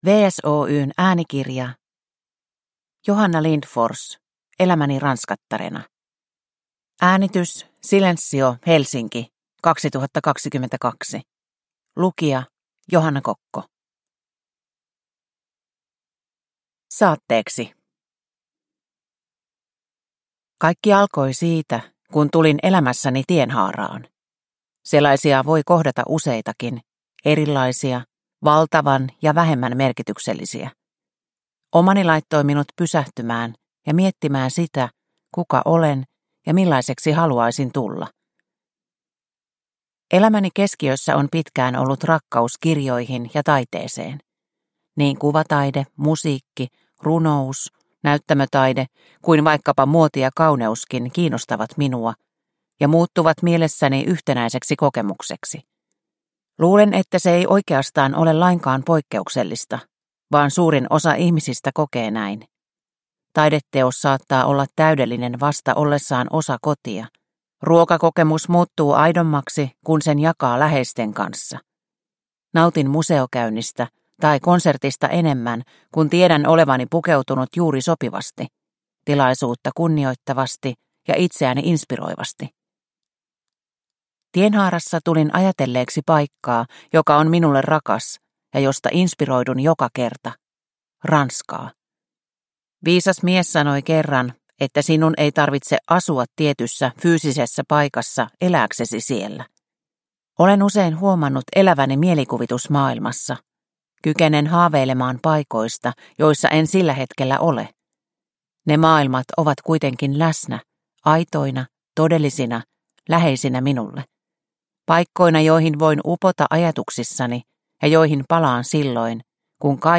Elämäni ranskattarena – Ljudbok – Laddas ner